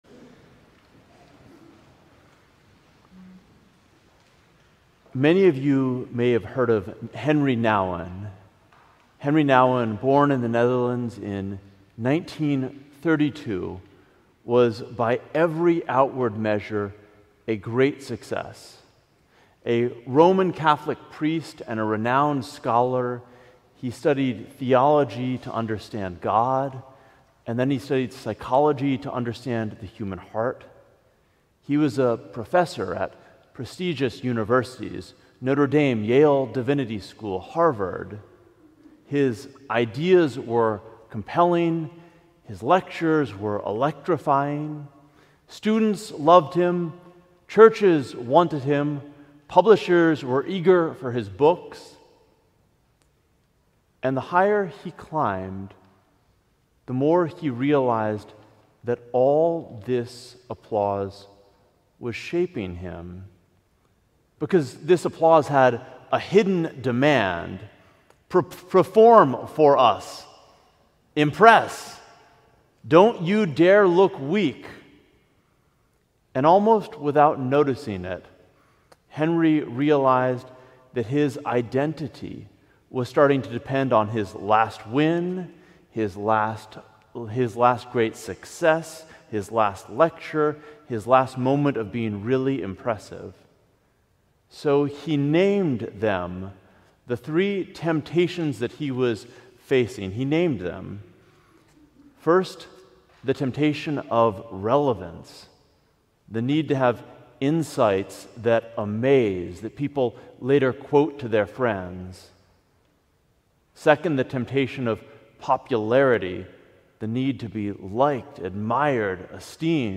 Sermon: Stop Pretending You're Always Fine - St. John's Cathedral